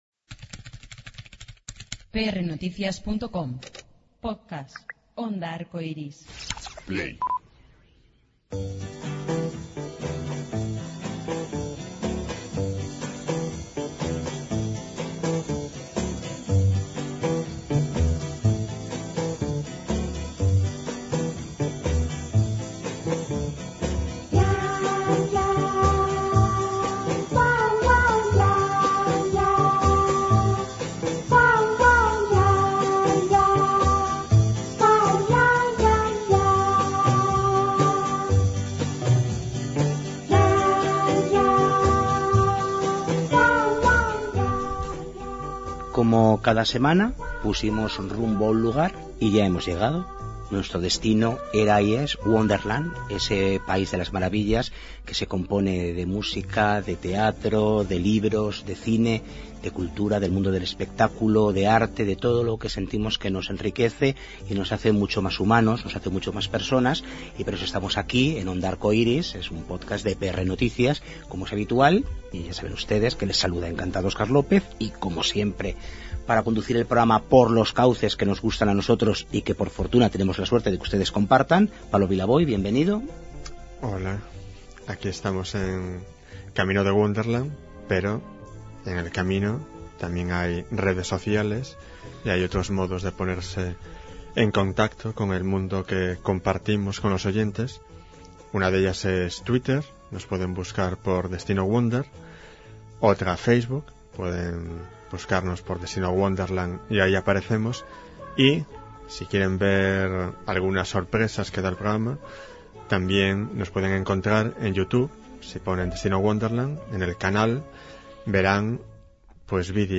Marta Ribera repite espectáculo, aunque con otro director. Y es que vuelve a formar parte del elenco de Cabaret, como bien nos cuenta en una extensa entrevista a Destino Wonderland.